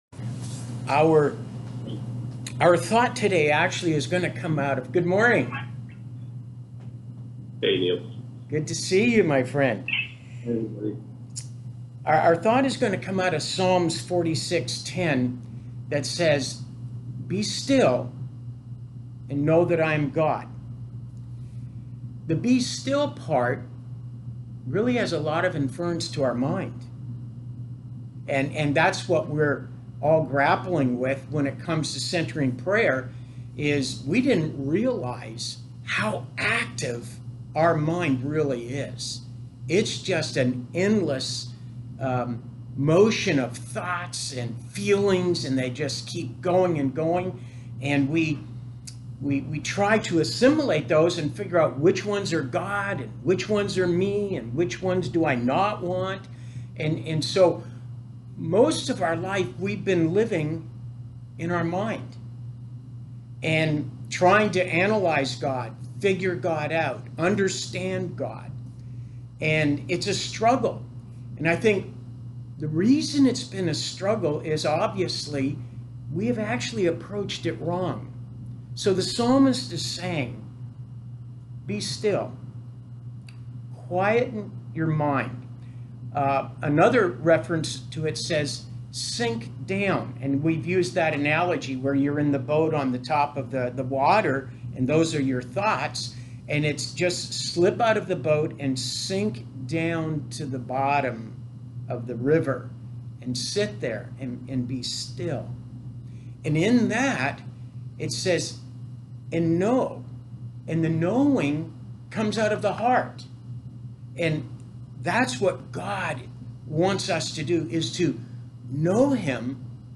Listen to the audio of the teaching session here Watch the video of the Q&A session after our live session of centering prayer on Saturday morning.